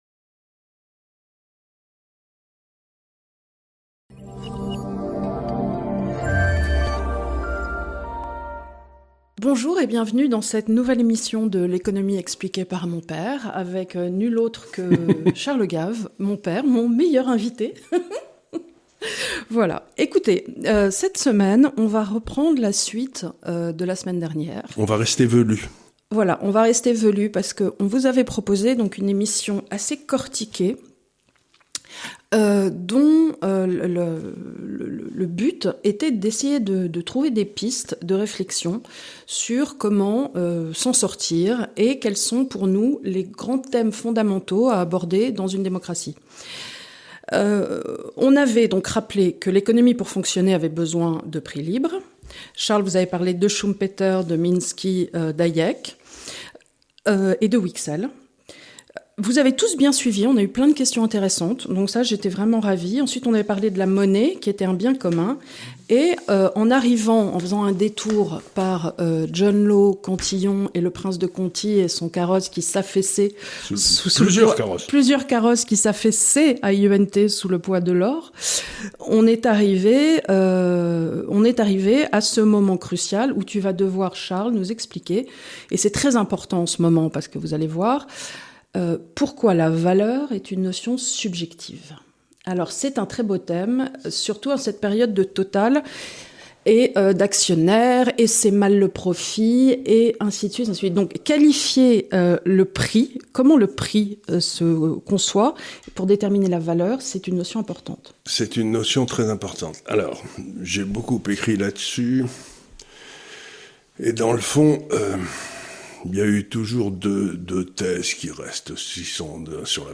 #55 - La valeur, Civilisation occidentale, Liberté individuelle. Un entretien avec Charles Gave.